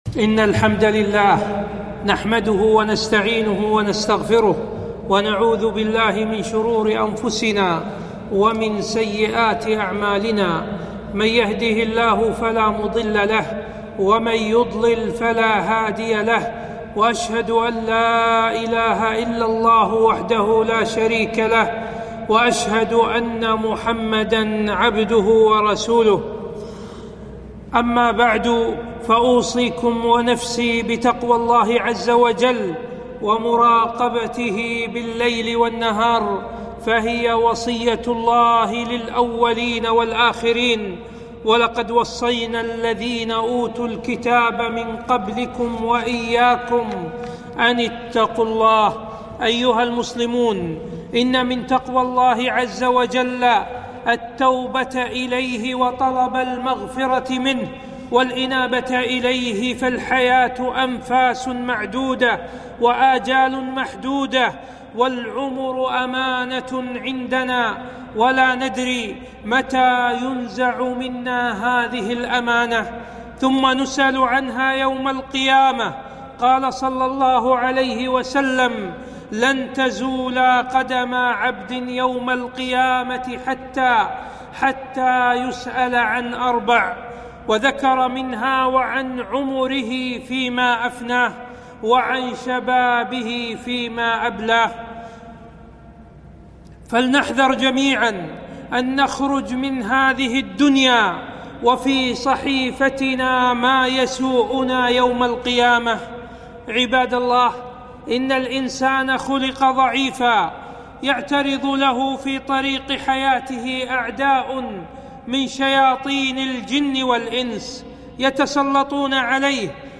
خطبة - وجوب التوبة وشروطها